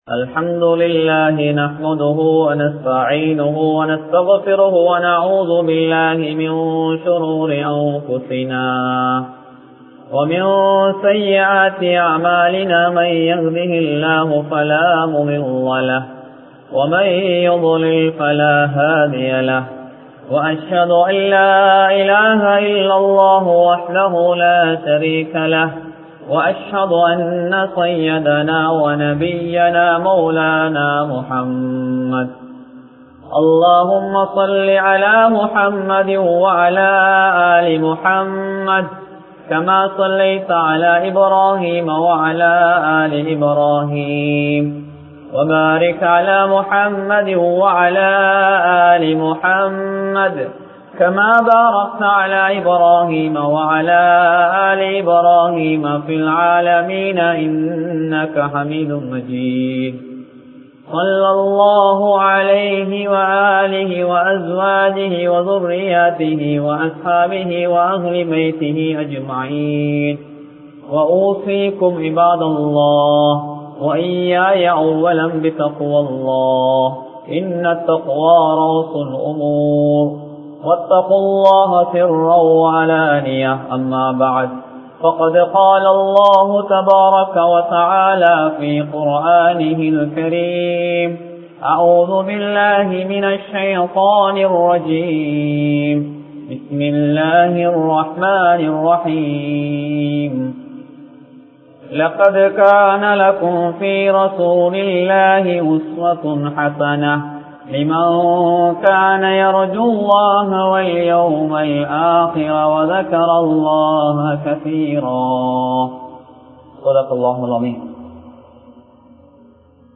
Nabi(SAW)Avarhalin MunMaathirihal (நபி(ஸல்) அவர்களின் முன்மாதிரிகள்) | Audio Bayans | All Ceylon Muslim Youth Community | Addalaichenai
Kurundugolla Jumuaha Masjith